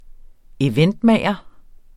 Udtale [ eˈvεndˌmæˀjʌ ]